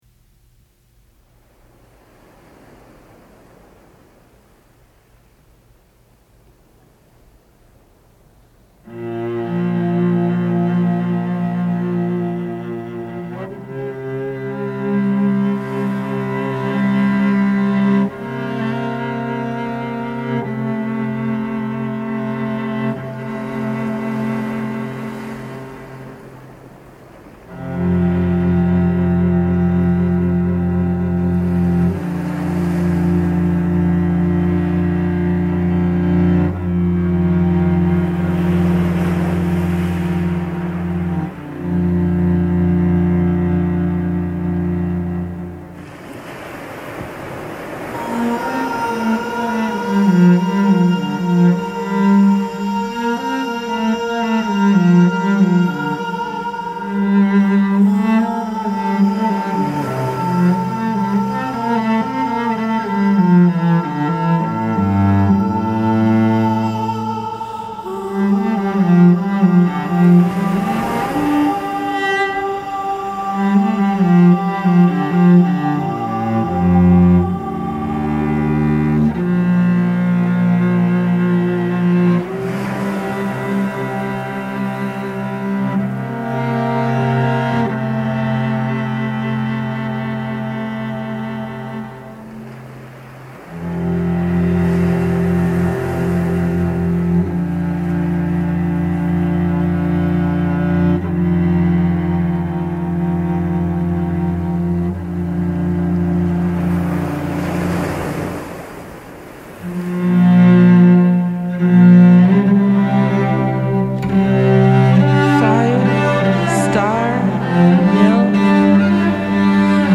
solo cello works